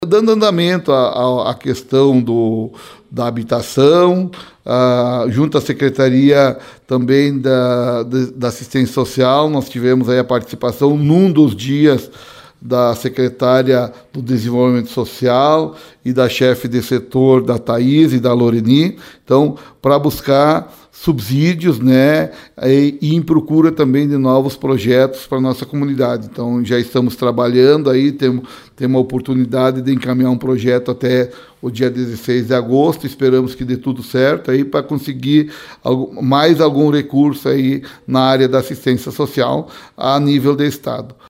Ouça a entrevista: 0808_JM_LASH_02 0808_JM_LASH_01